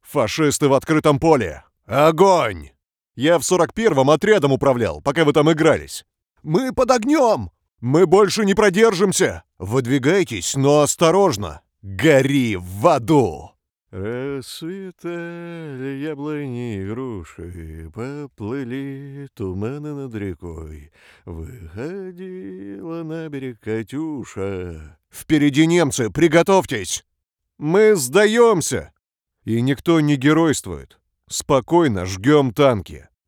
Bilingual Russian and Ukrainian Voice Over Talent with own studio
Sprechprobe: Sonstiges (Muttersprache):
Russian Soldier  Game Character 2018.mp3